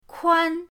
kuan1.mp3